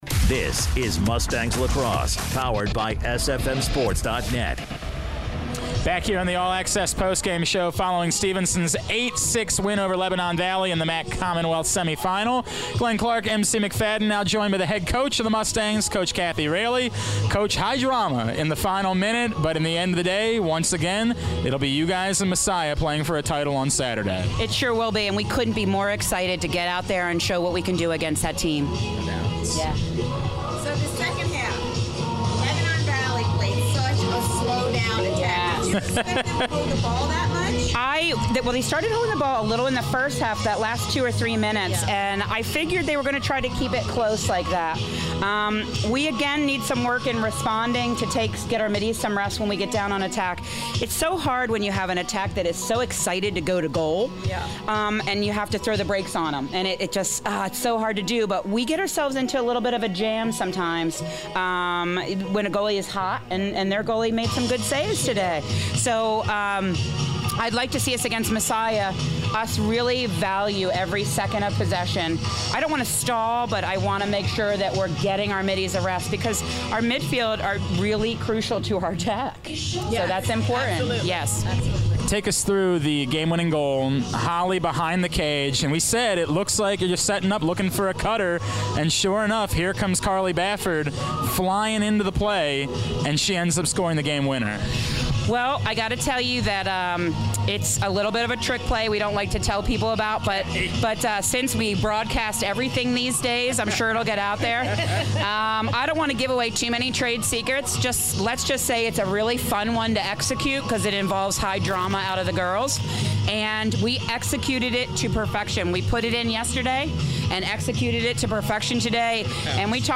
5/4/16: Stevenson Women's Lax Post Game Show